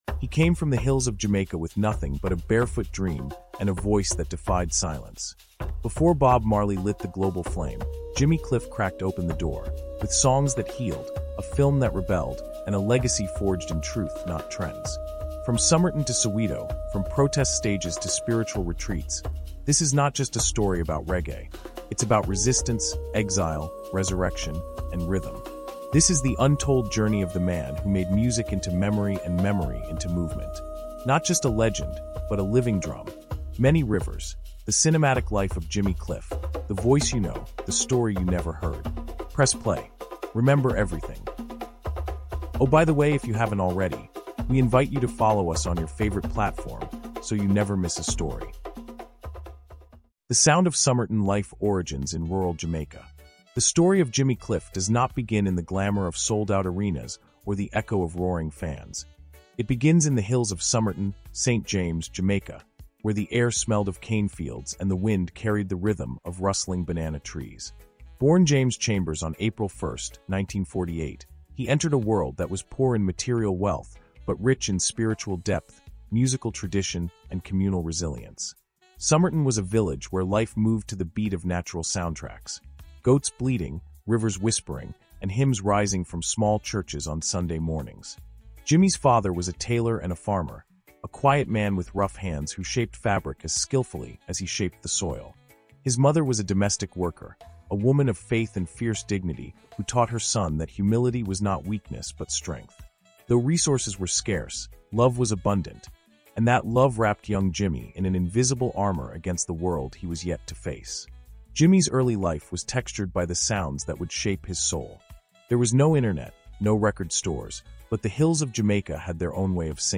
Told through immersive storytelling and emotionally driven narration, this isn’t just history—it’s a living soundscape of the Caribbean diaspora.
Many Rivers: The Cinematic Life of Jimmy Cliff is a breathtaking, first-person Caribbean podcast documentary that traces the extraordinary journey of reggae’s first global icon. From the sugarcane fields of rural Jamaica to international stardom, spiritual awakening, and cultural immortality—this story is as much about resilience and cultural identity as it is about music.